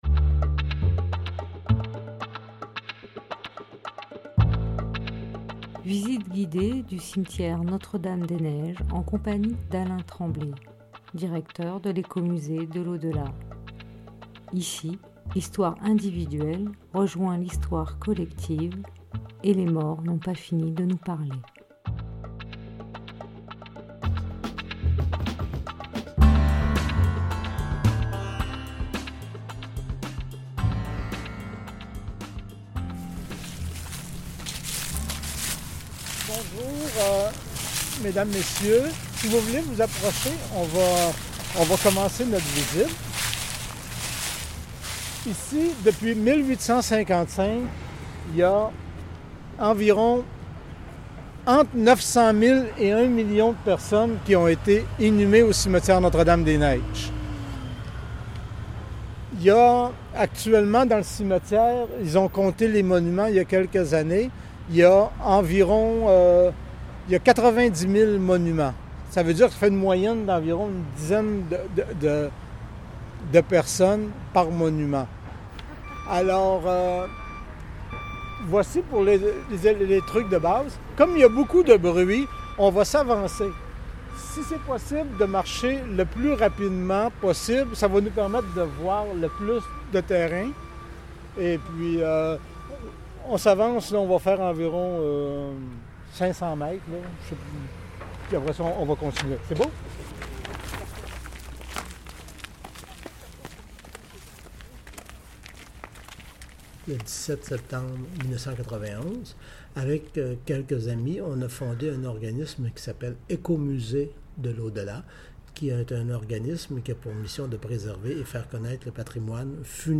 Visite guidée du cimetière Notre-Dame-des-Neiges